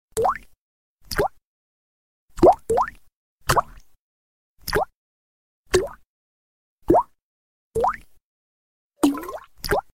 kapaiushaia-voda_24578.mp3